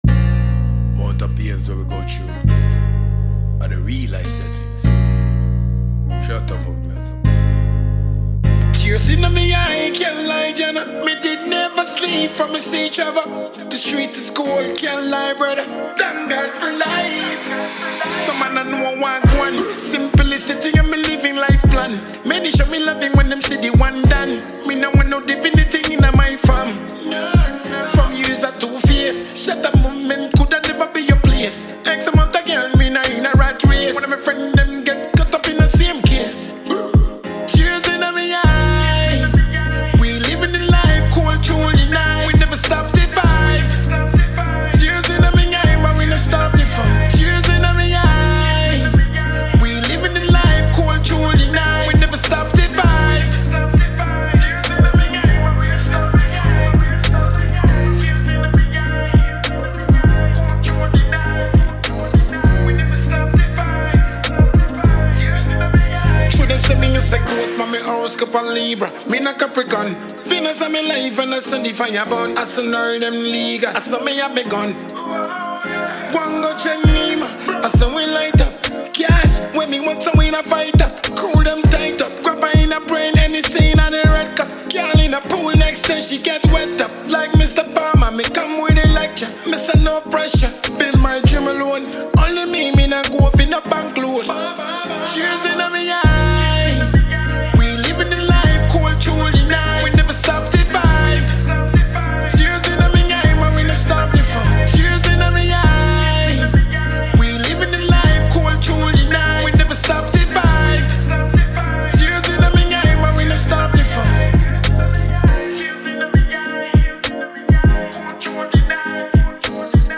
a Ghanaian dancehall act